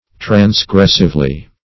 Transgressively \Trans*gress"ive*ly\, adv.